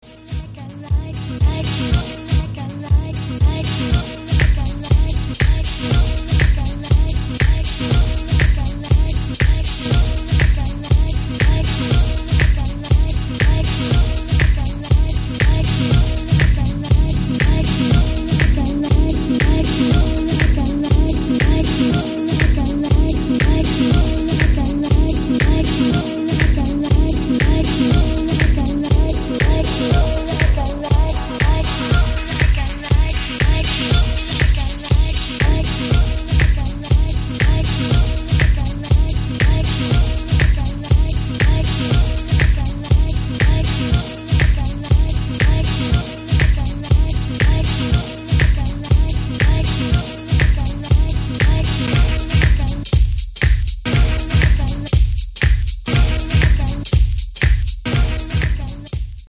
two warm house tracks with a nice laidback funky feel.